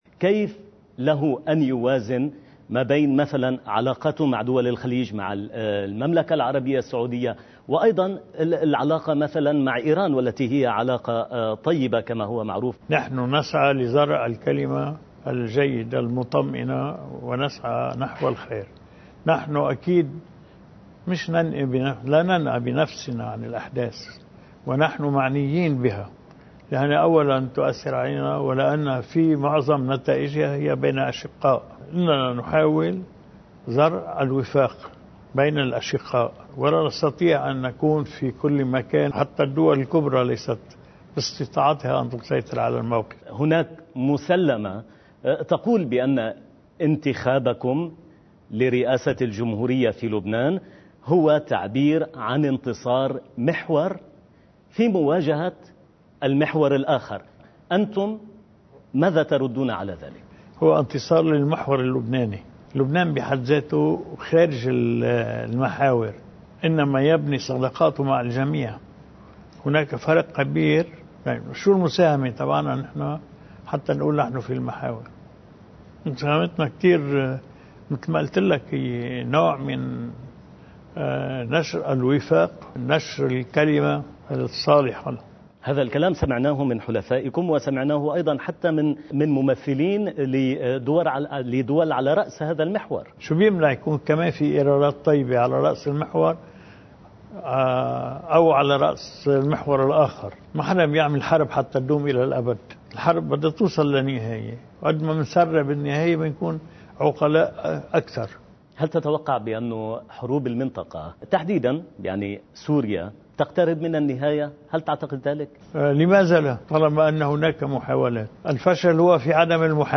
مقتطف من حديث الرئيس عون لقناة الجزيرة: (عن سياسة المحاور ودور لبنان وقطر والسعودية)